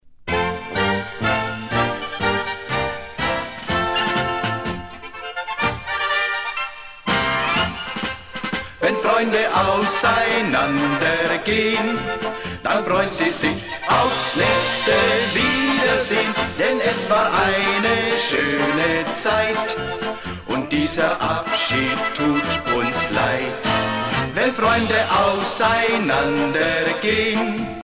danse : marche